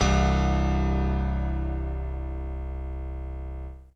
01 SY99 Piano C1.wav